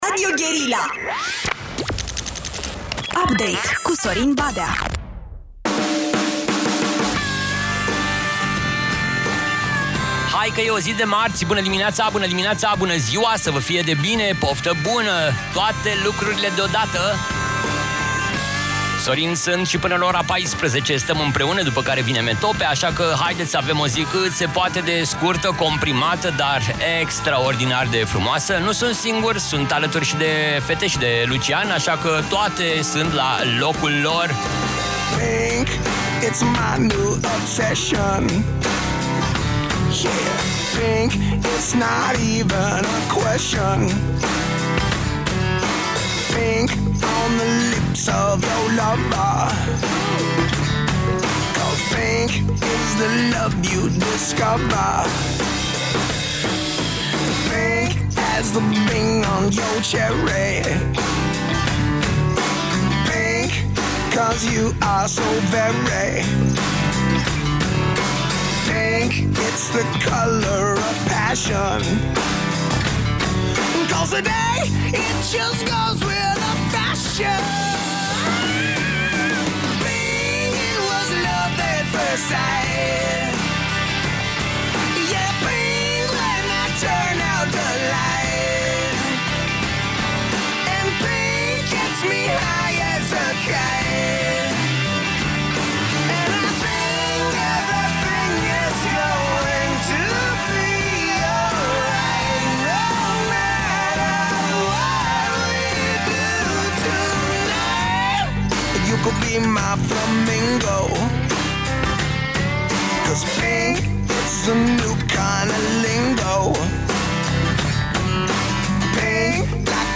Invitați, discuții și muzică, adică un meniu obișnuit.